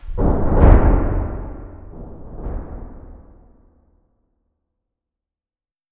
truenitos_1.wav